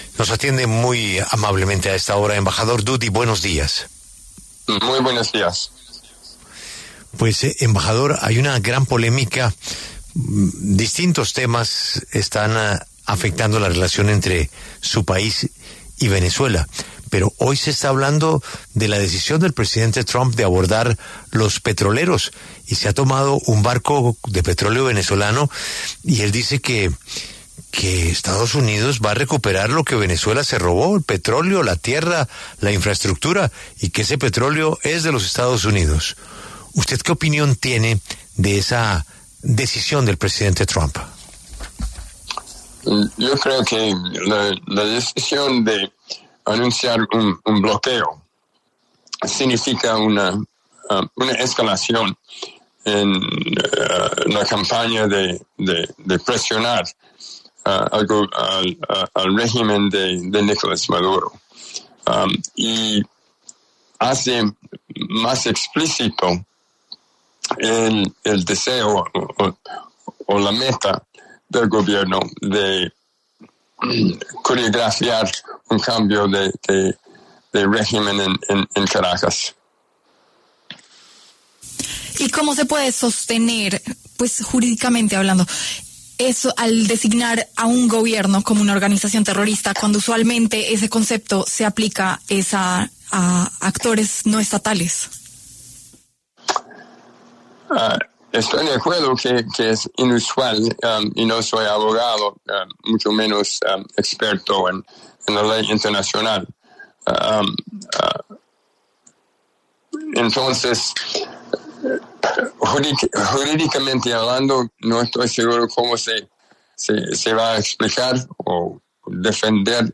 El diplomático Patrick Dutty, exembajador de los Estados Unidos en Venezuela, habló en La W sobre la relación entre ambos países luego de que el Gobierno de EE.UU. decidiera recuperar el petróleo que se habría “robado” Venezuela.